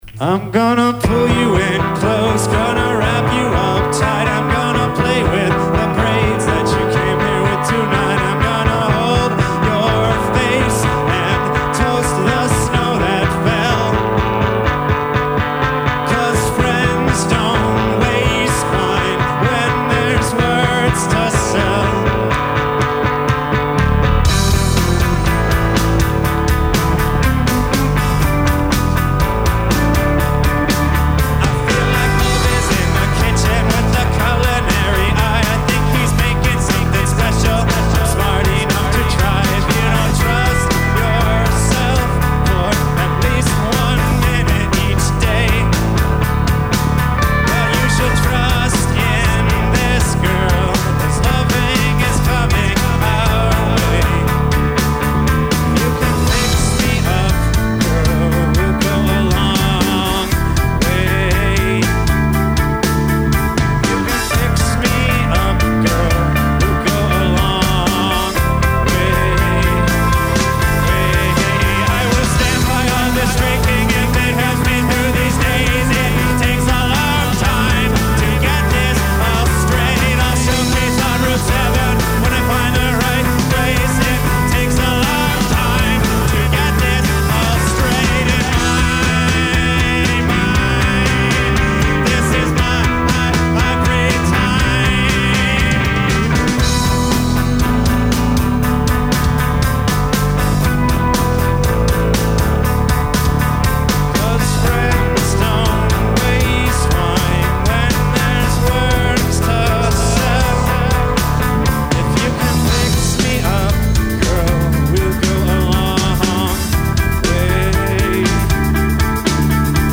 enregistrée le 27/08/2002  au Studio 105